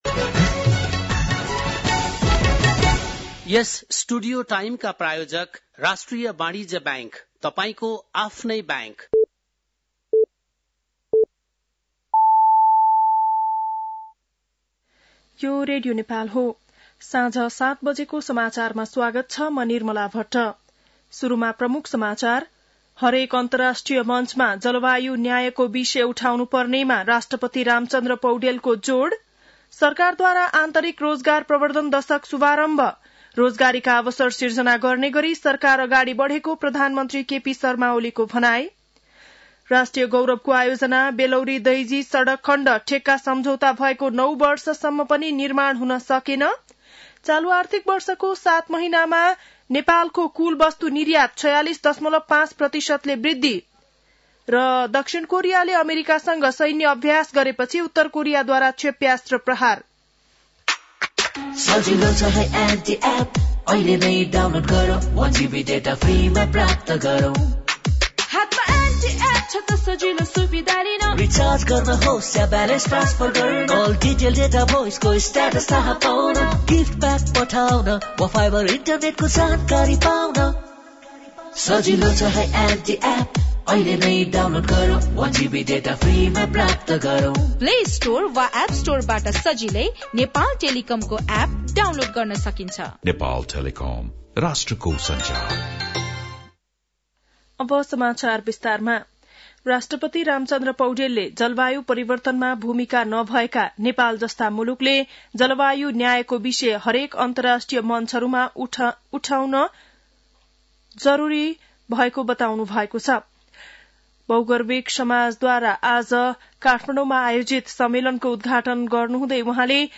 बेलुकी ७ बजेको नेपाली समाचार : २७ फागुन , २०८१
7-pm-nepali-news-11-26.mp3